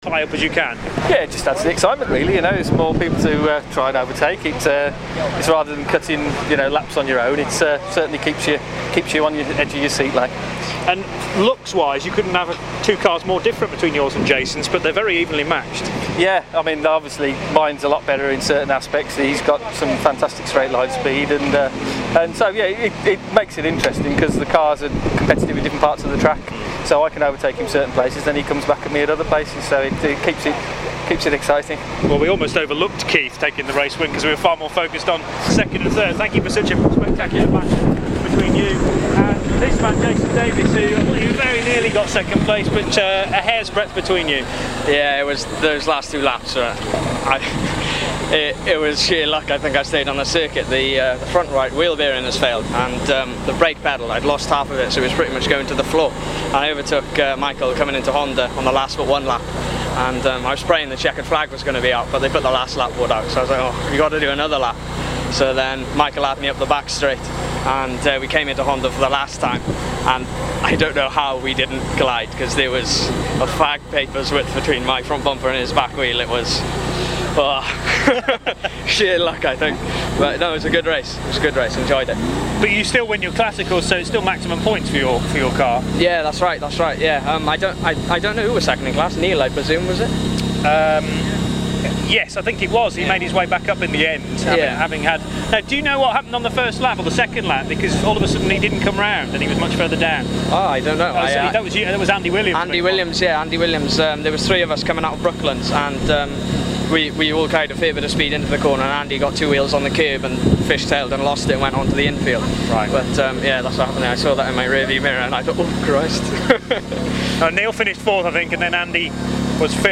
Race 1 interveiws.mp3